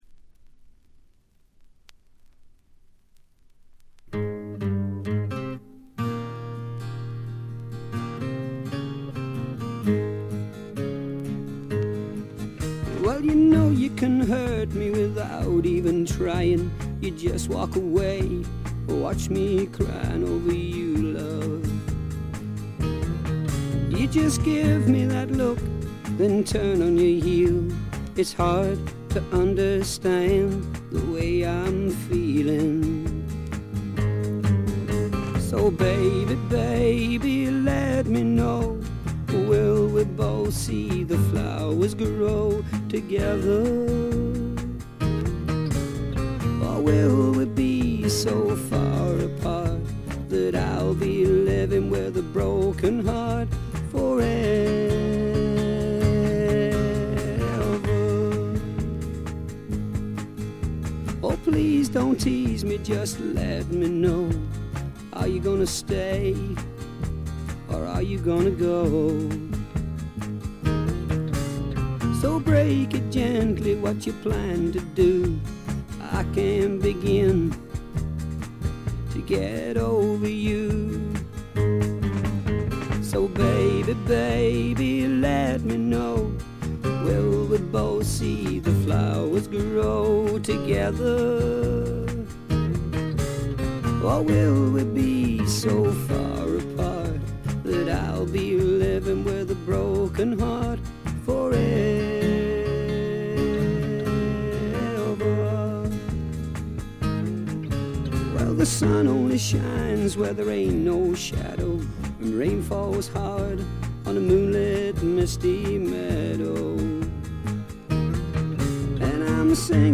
これ以外はところどころでチリプチ。
英国のフォーキーなシンガー・ソングライター
弾き語りに近いような控えめでセンスの良いバックがつく曲が多く、優しい歌声によくマッチしています。
試聴曲は現品からの取り込み音源です。